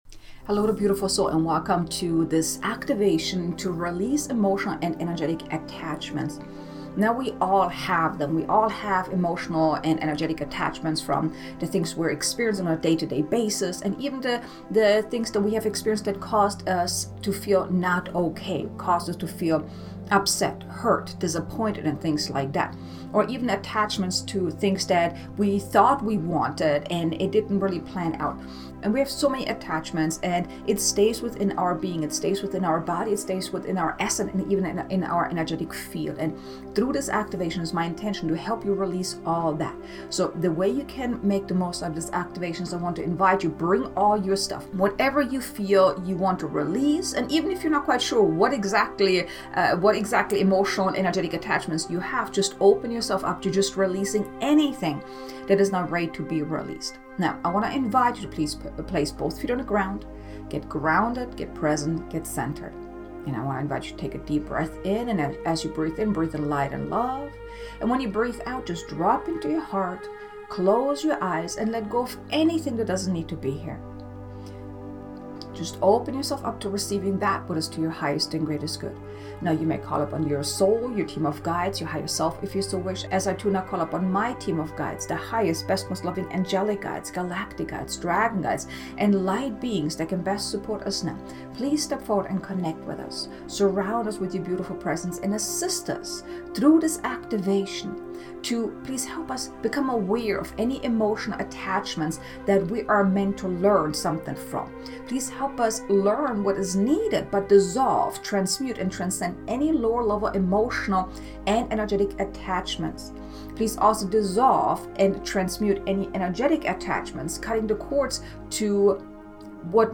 Light Language Activation: Releasing Emotional & Energetic Attachments